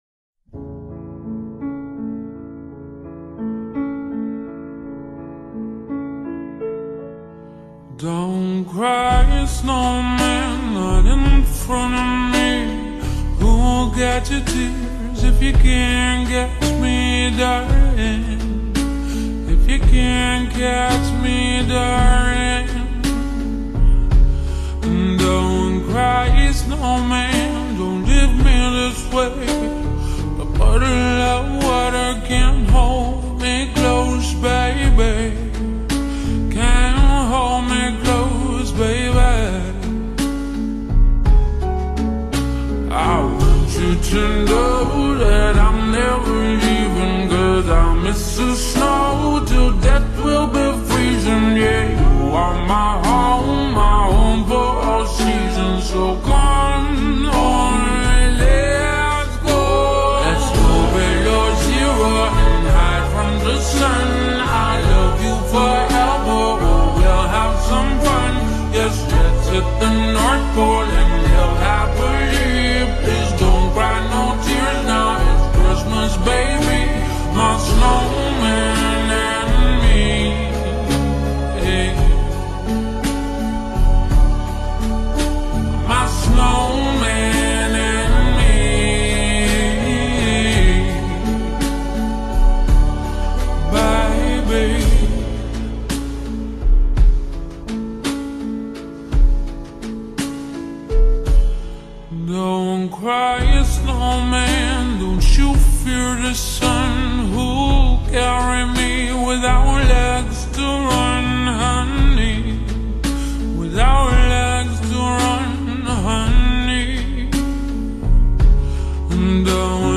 نسخه Slowed